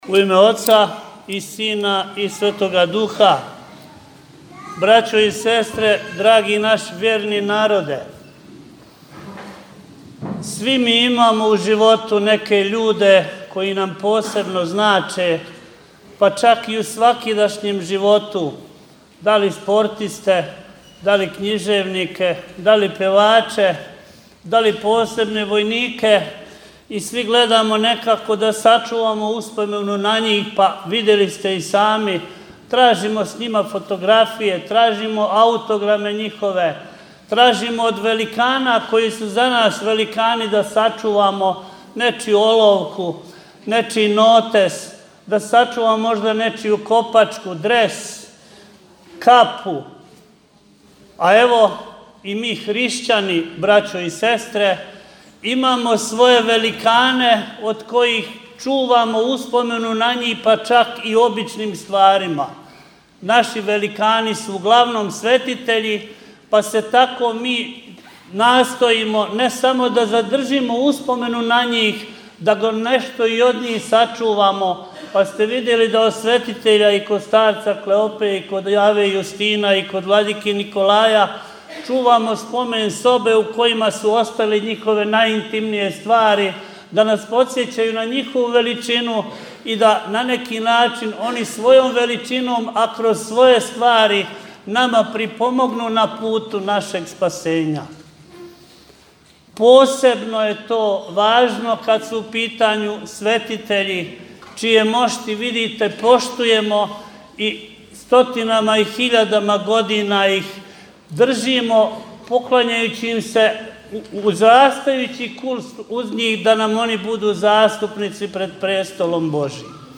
Велику светињу дочекало је више од 700 у молитви сабраних душа, које су достојанствено и у миру прилазиле светињи да је целивају.